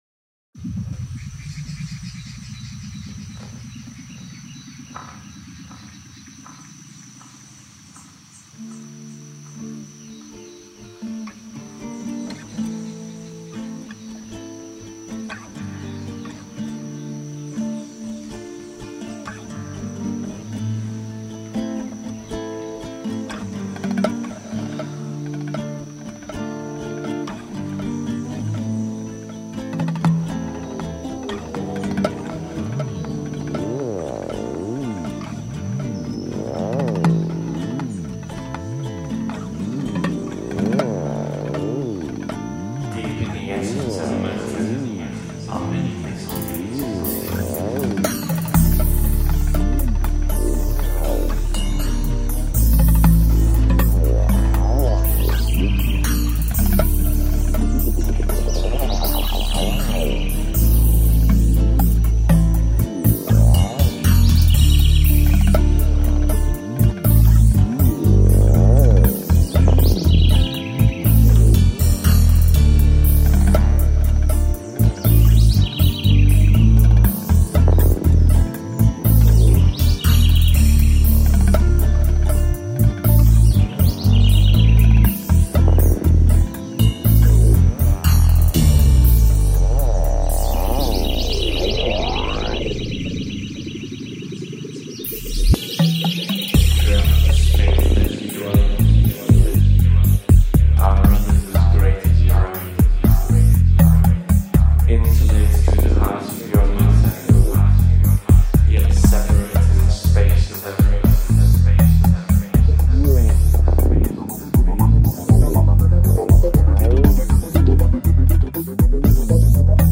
"Deep tribal magick.."